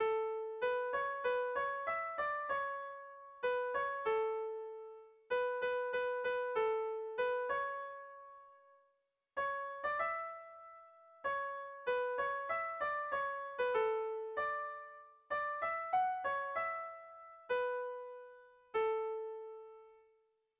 Bertso melodies - View details   To know more about this section
Lauko txikia (hg) / Bi puntuko txikia (ip)
AB